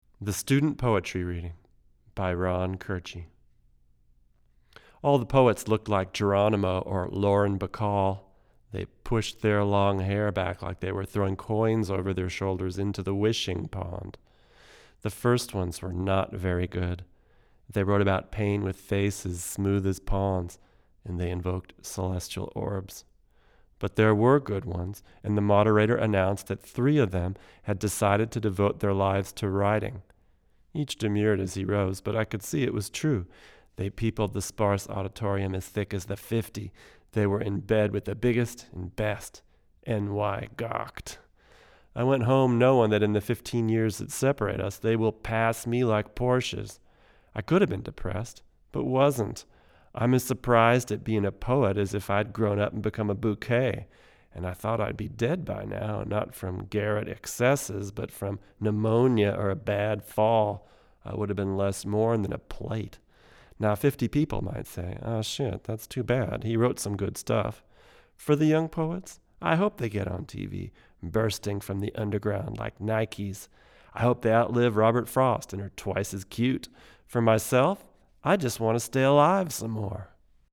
Here are 2 dozen quick, 1-take MP3s using this U87 in a large room -- running on battery power -- into a Sony PCM D1 flash recorder (which does not have P48 Phantom Power), with MP3s made from Logic. These tracks are just straight signal with no additional EQ, compresson or effects:
VOICE OVER:
VOU87cD1English.mp3